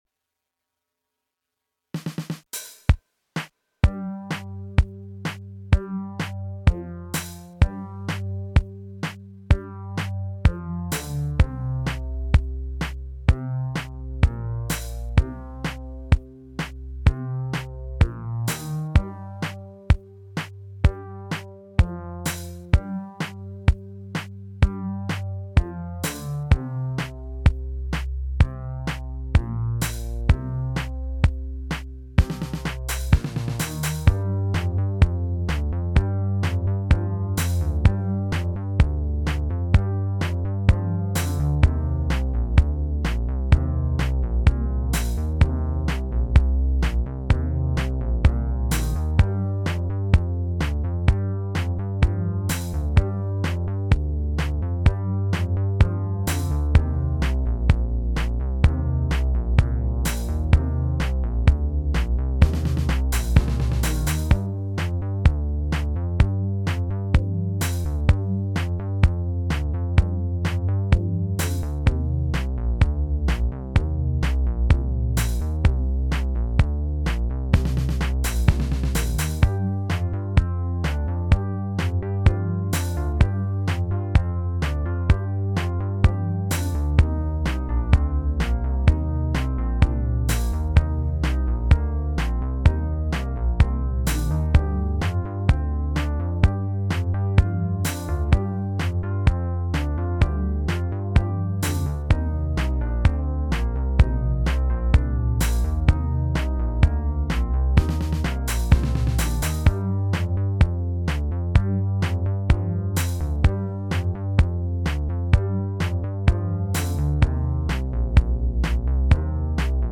It's my first time actively trying this kind of music (the whole 80s vibes thing), so it may not have come out great, but I'm still learning of course.
Of course, this is something I put together quickly to meet the deadlines I set for myself, so it's a bit unrefined.
That may cause some drop in quality, but I have nowhere to upload higher quality at the moment (am looking into server hosting options to host my own website and stuff, but that's not for awhile).
80s synth bass music